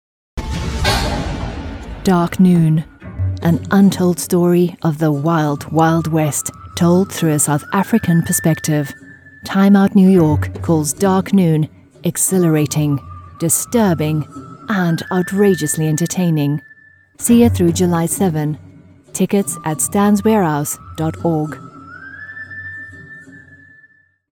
Filmtrailer
Meine Stimme kann zugänglich und freundlich, bestimmend, warm und glaubwürdig oder auch schrullig und lebhaft sein.
Schallisolierter Raum
HochMezzosopran